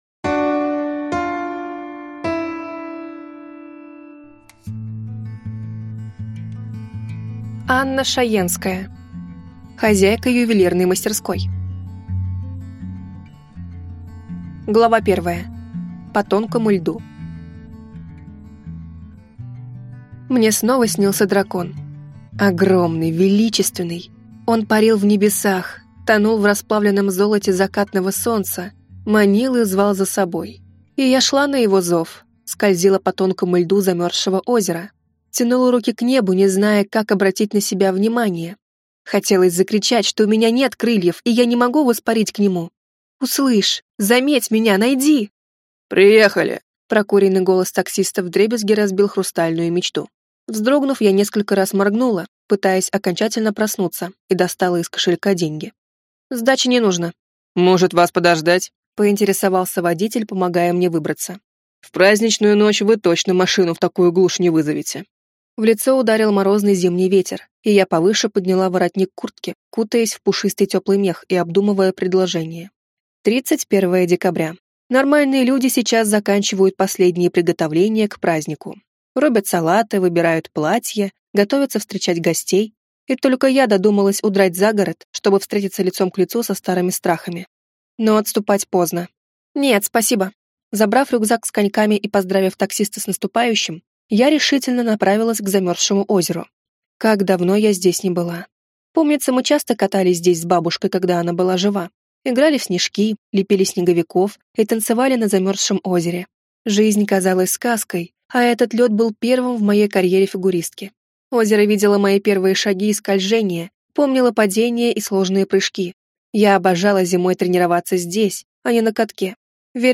Аудиокнига Хозяйка ювелирной мастерской | Библиотека аудиокниг